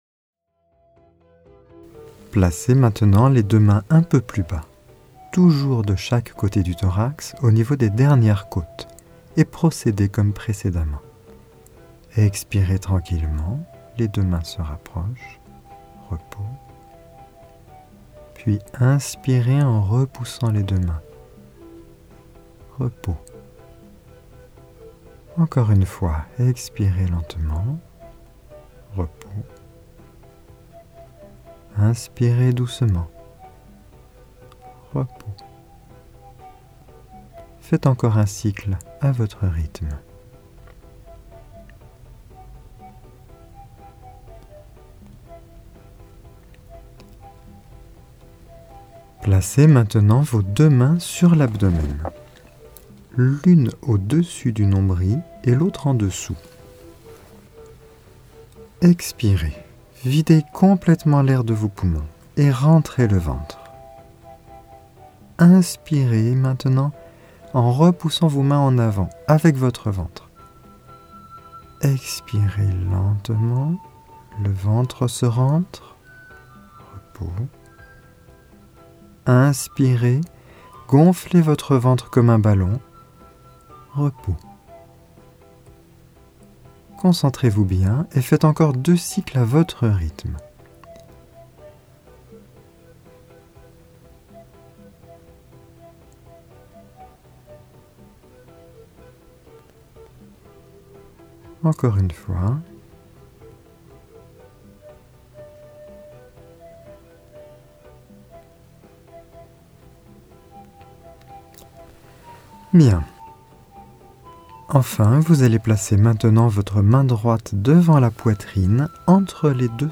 Genre : Meditative.